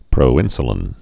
(prō-ĭnsə-lĭn)